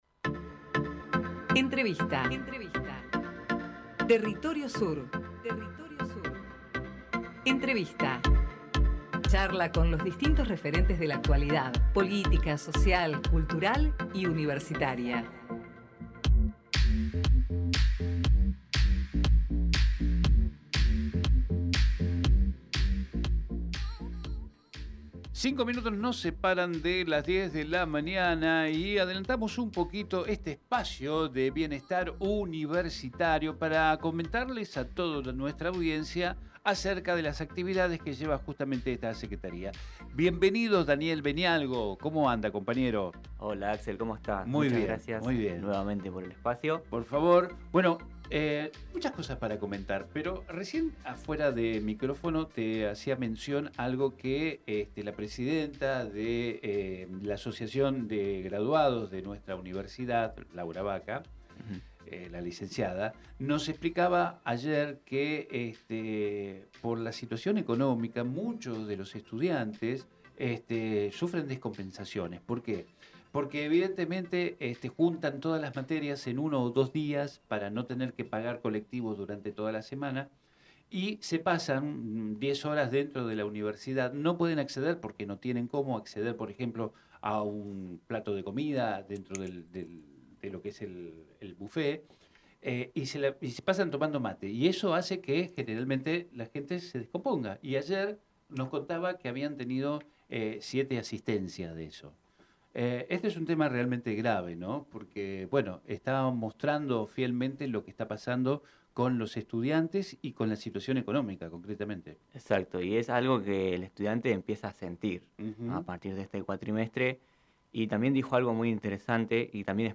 Compartimos la entrevista realizada en Territorio Sur